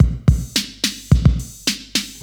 • 108 Bpm Breakbeat A# Key.wav
Free drum loop - kick tuned to the A# note. Loudest frequency: 1751Hz
108-bpm-breakbeat-a-sharp-key-1tm.wav